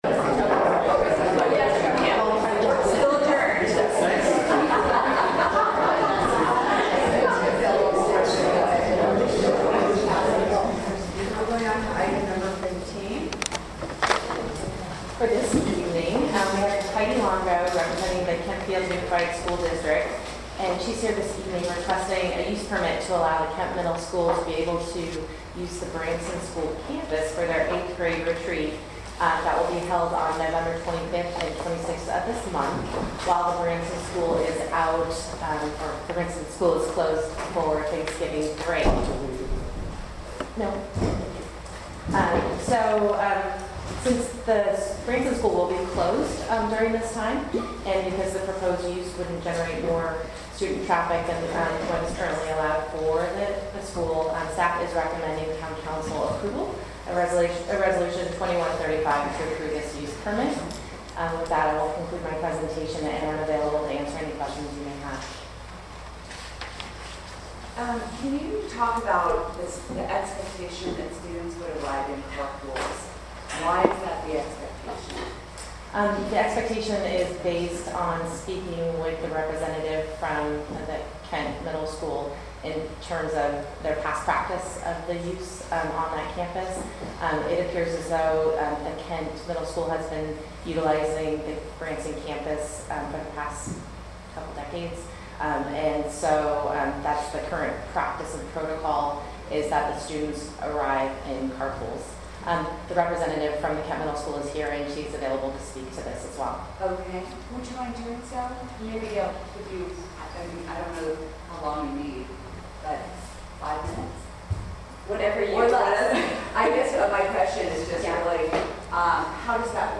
Town Council Meeting | Town of Ross California